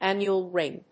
アクセントánnual ríng